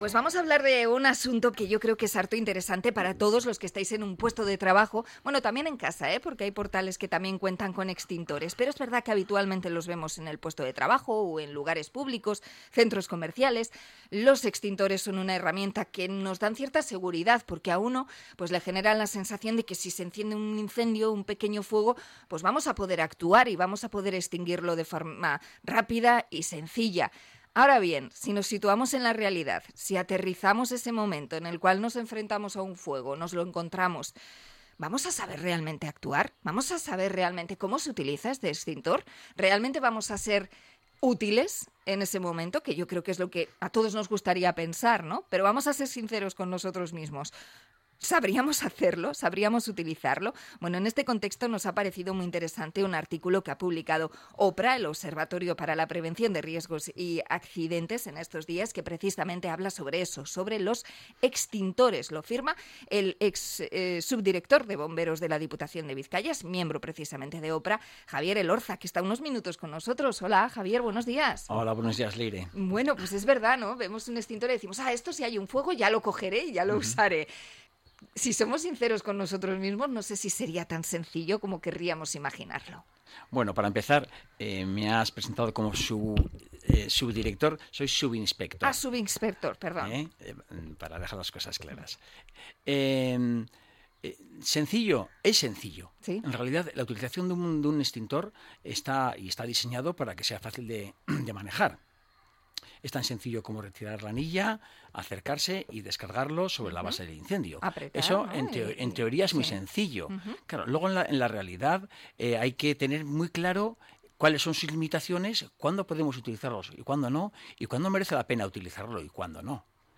Entrevista a especialista en incendios sobre los extintores